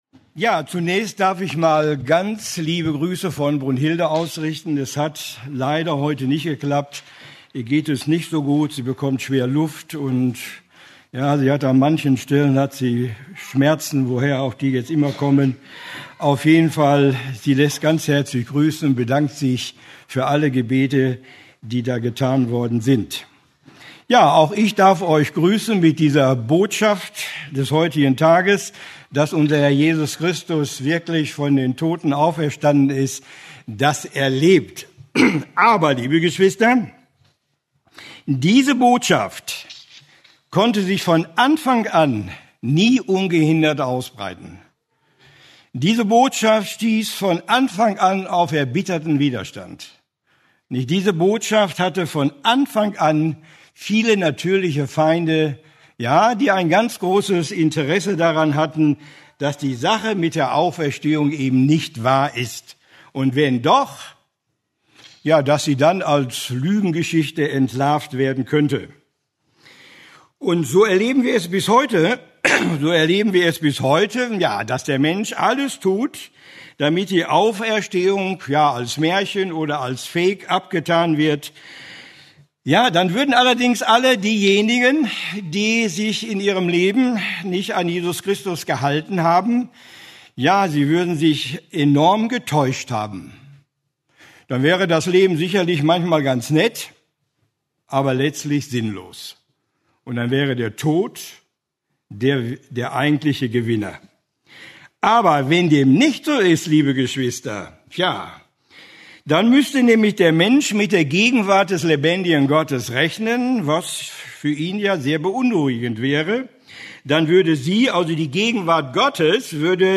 Eine predigt aus der serie "Einzelpredigten."